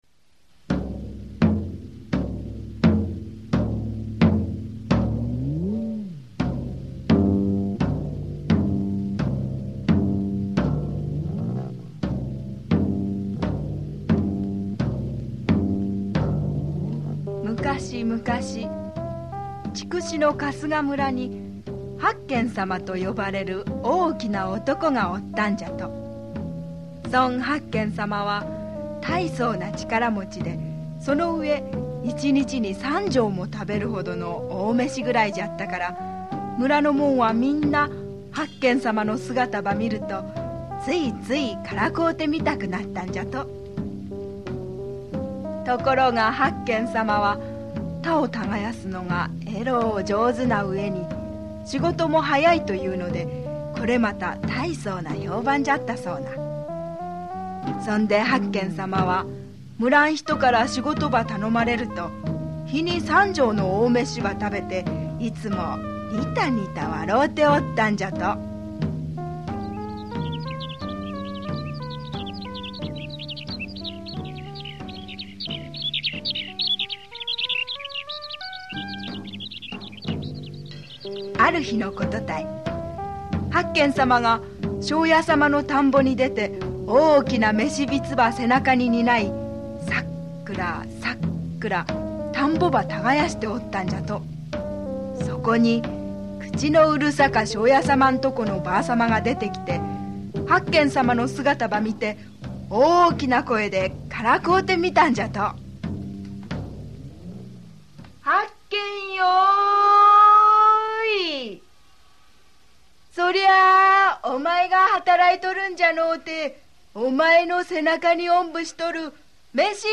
[オーディオブック] 伯賢さま